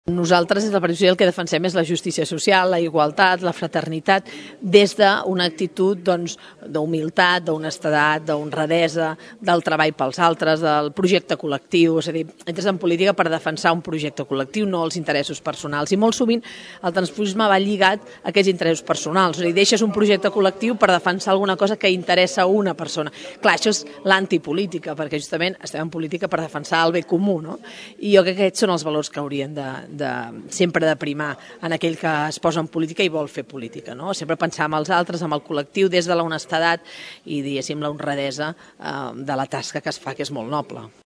En declaracions a Ràdio Tordera, Romero defineix com a delicada la situació política al nostre municipi i es planteja diverses qüestions.